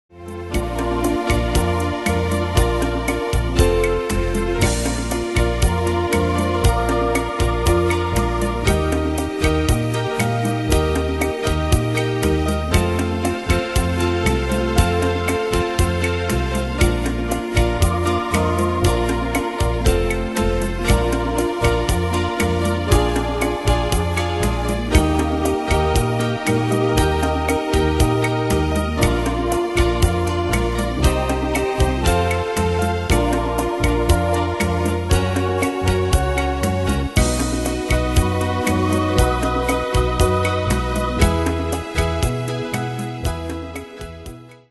Style: PopFranco Ane/Year: 1990 Tempo: 118 Durée/Time: 3.36
Danse/Dance: ChaCha Cat Id.